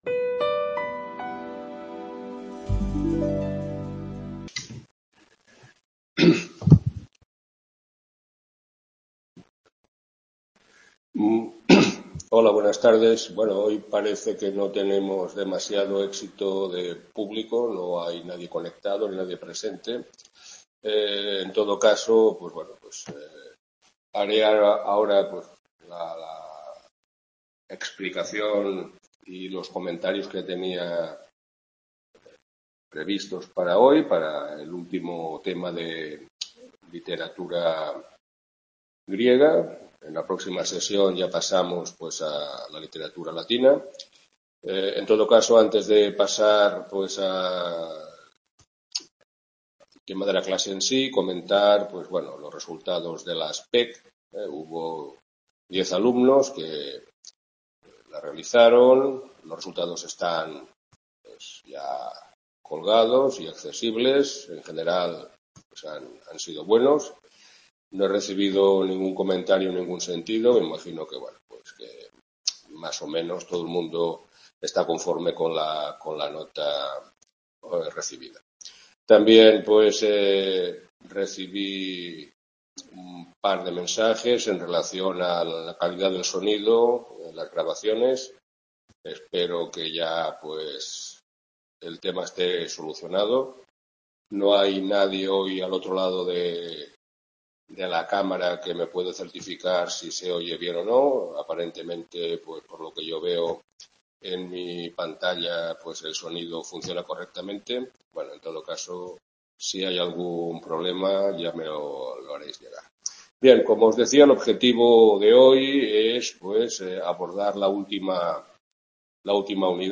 Tutoría 01/04 literatura clásica | Repositorio Digital